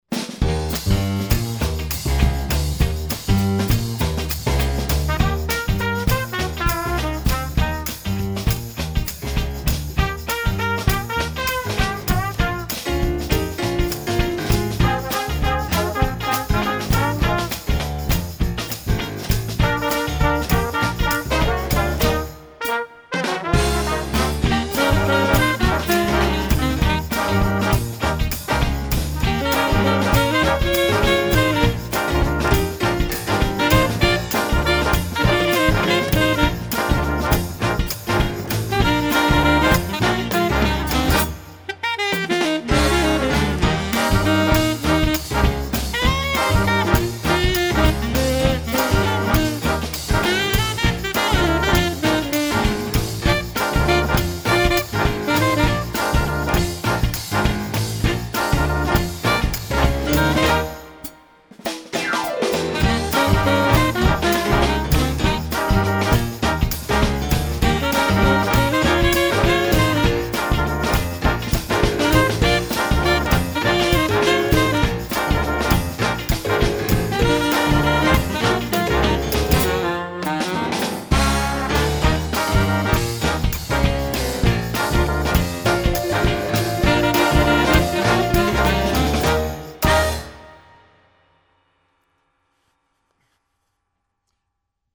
10 new orleans styled show themes